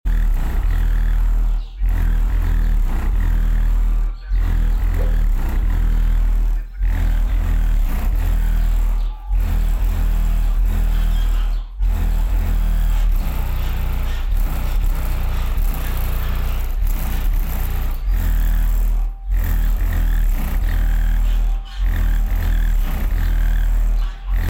Deaf Bonce sa-305 38Cm Subwoofer